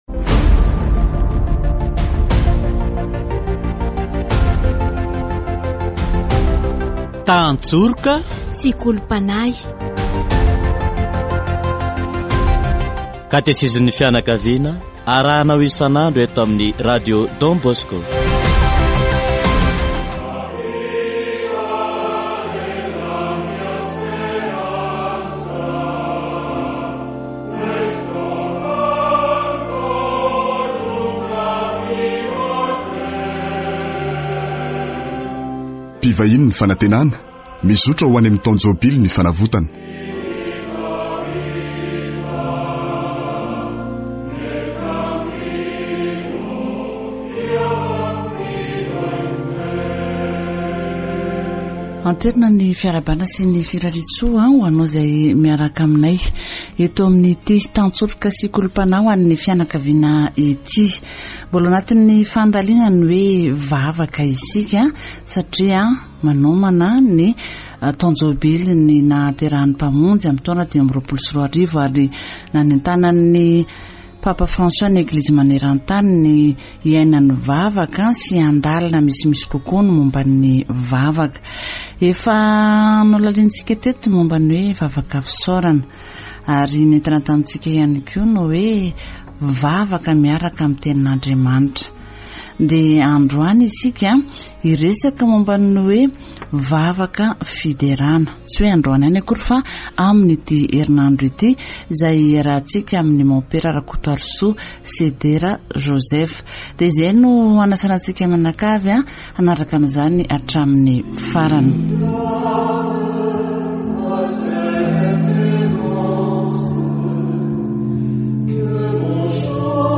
Category: Deepening faith